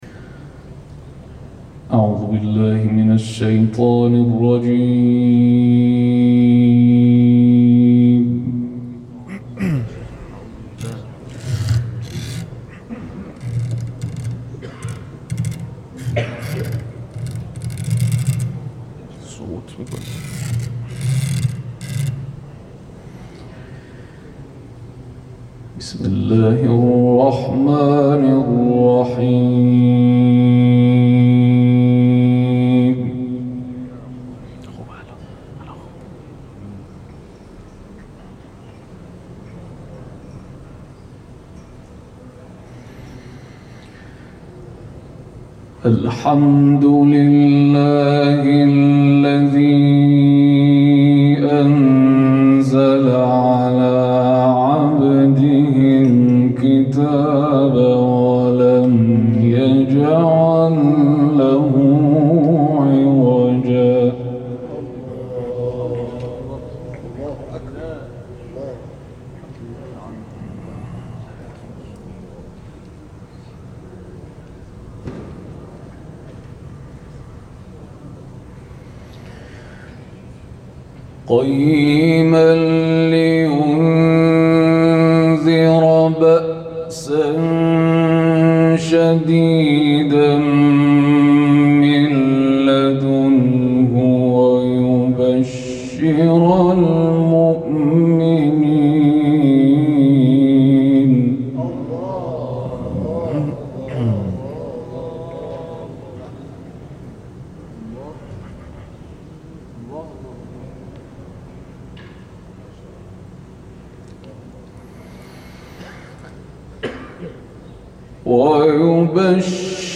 تلاوت
در جلسه مسجد جامع المهدی